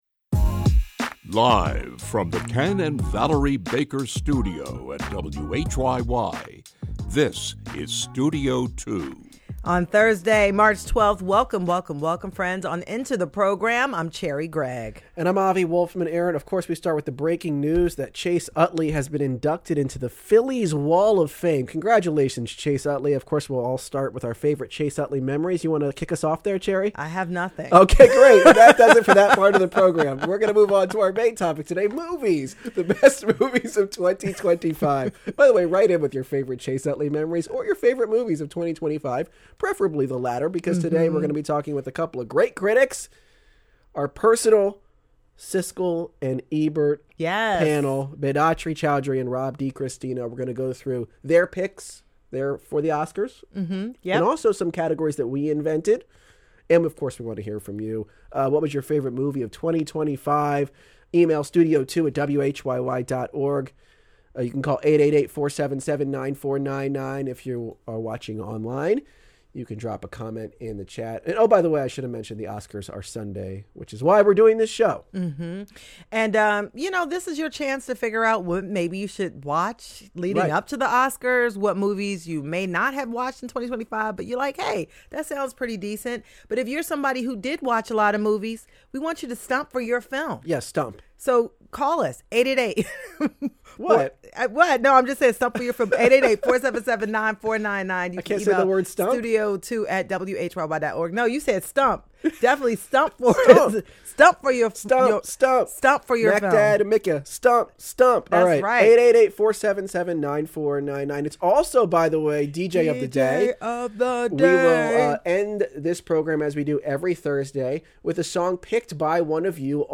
On the first day of spring, we invite two master gardeners to answer your questions about planting, growing, weeding, mulching and more.